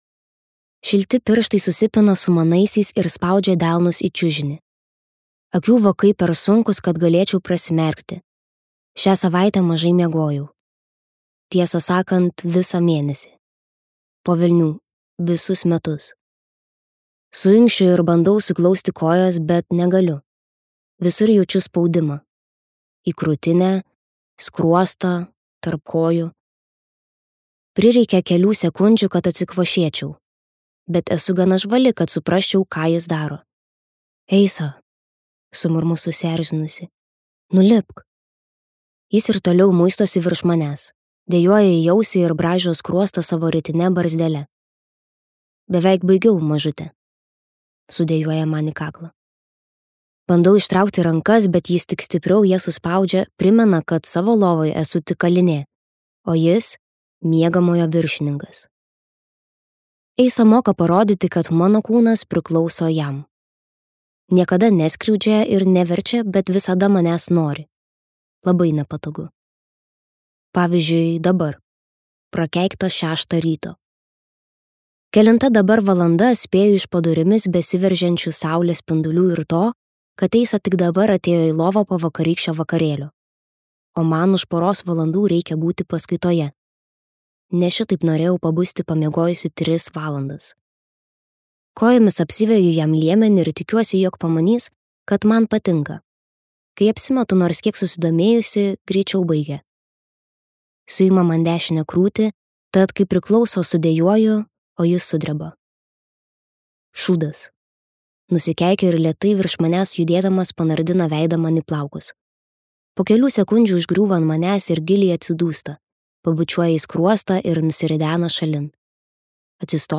Per vėlu | Audioknygos | baltos lankos